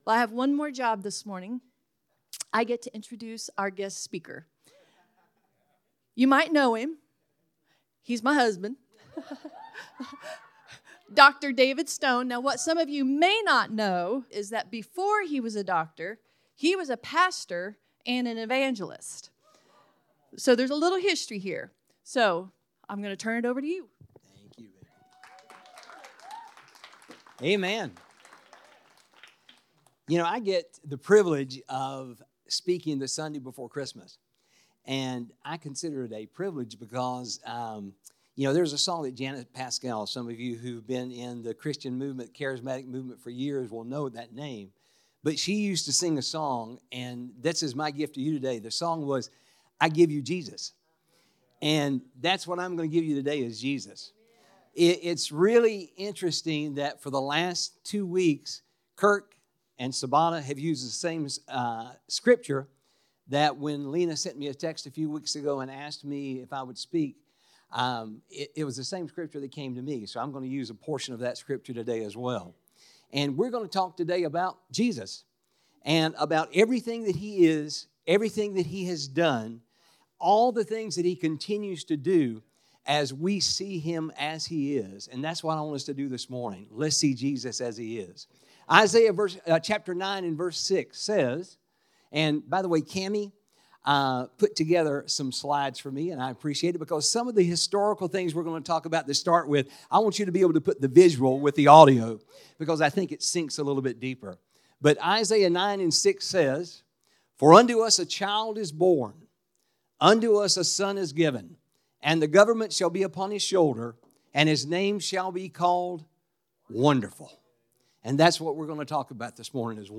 SERMONS
Epicenter Church